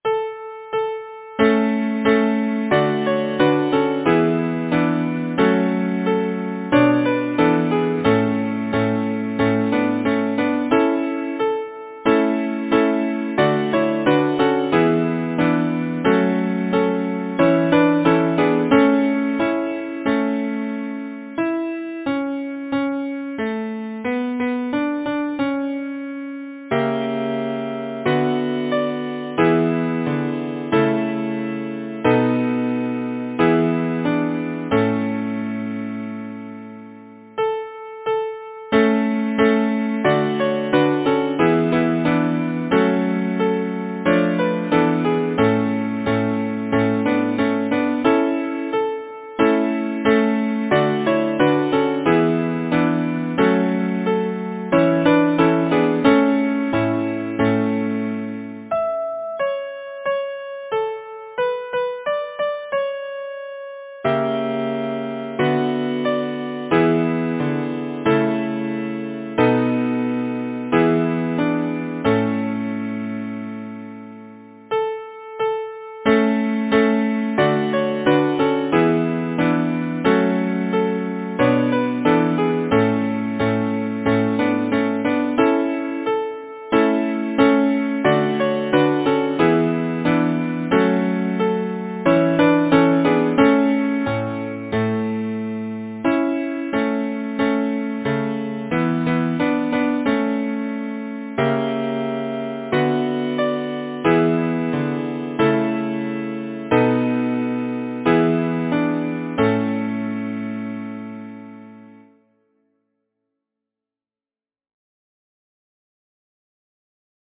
Title: Roses of the Sea Composer: Francis Amcotts Jarvis Lyricist: George Spencer Cautley Number of voices: 4vv Voicing: SATB Genre: Secular, Partsong
Language: English Instruments: A cappella